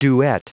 Prononciation du mot duet en anglais (fichier audio)